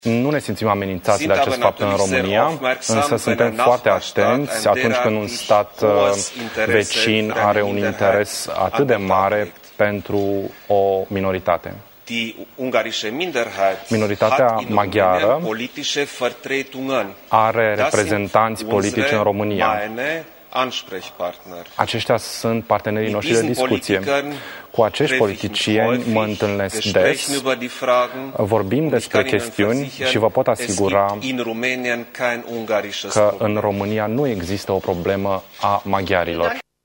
Întrebat în cadrul conferinţei de presă comune susţinută cu Cancelarul Germaniei, Angela Merkel despre acest subiect, Iohannis a precizat că minoritatea maghiară are reprezentanţi politici în România, care sunt partenerii lui de discuţie.